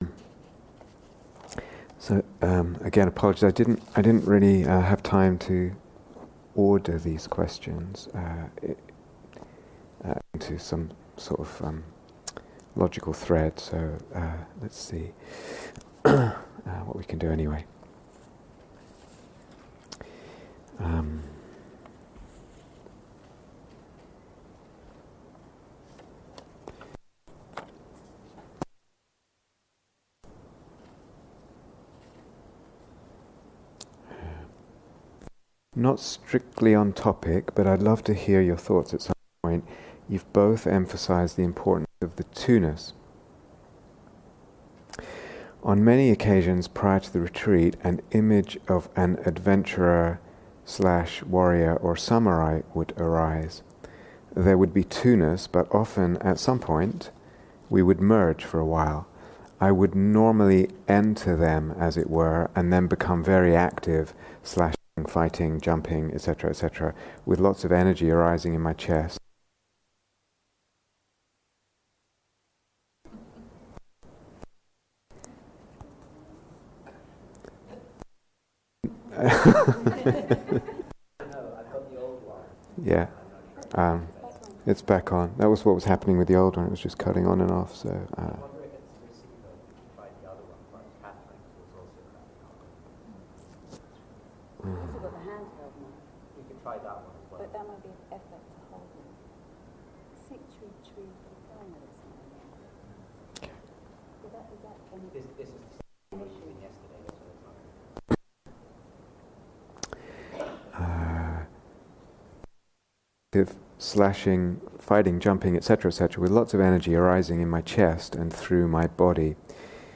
Eros and Desire (Q & A)
Download 0:00:00 68:53 Date 27th March 2019 Retreat/Series Roots into the Ground of Soul